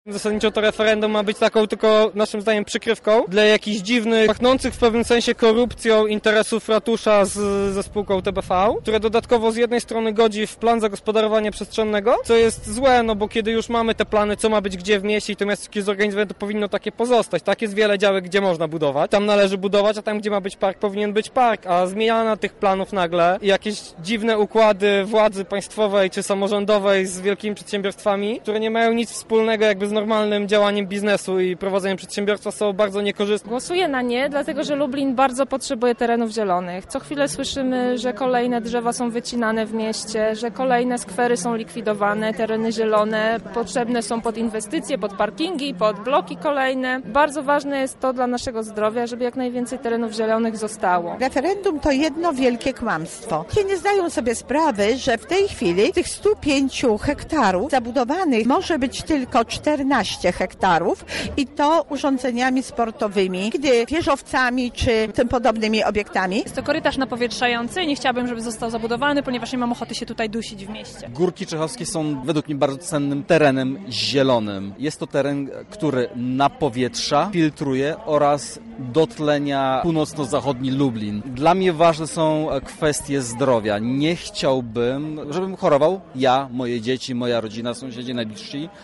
Posłuchajmy jak postrzegają one pomysł głosowania w referendum:
Sonda